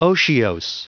Prononciation du mot otiose en anglais (fichier audio)
Prononciation du mot : otiose